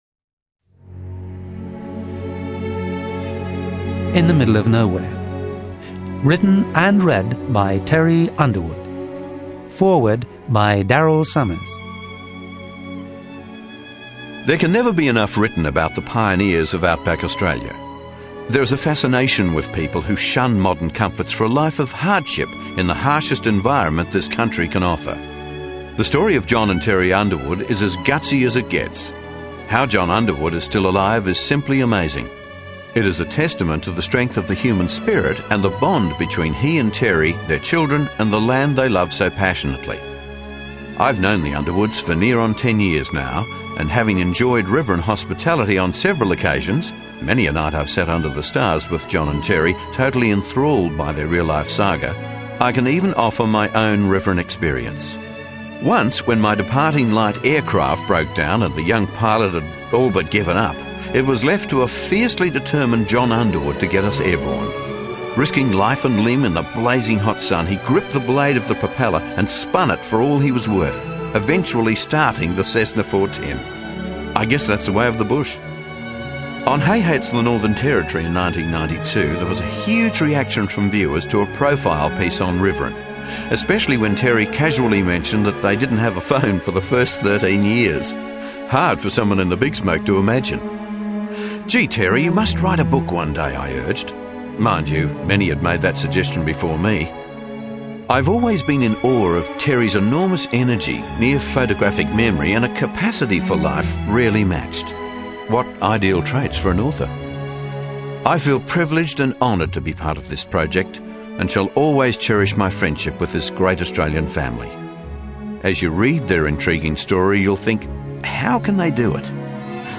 Format: Unabridged CD Audio Book